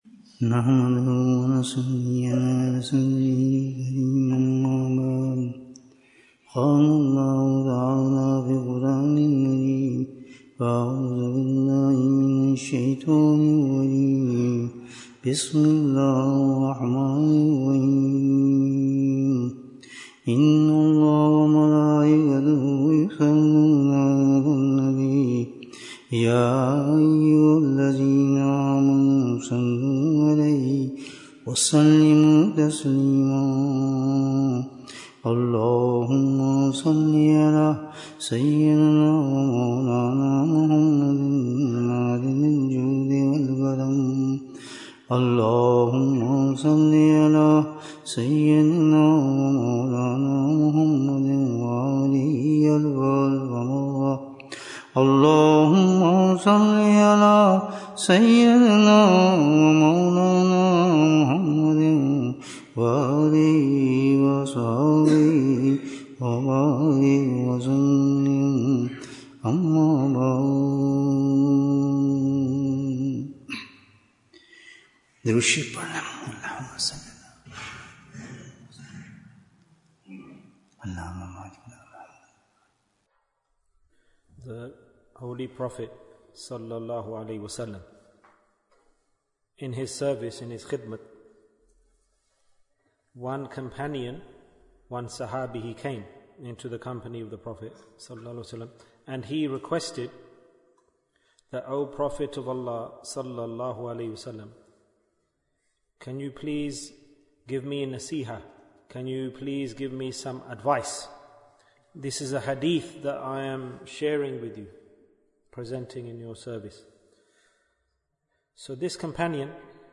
A Message of a Sahabi Bayan, 34 minutes27th June, 2024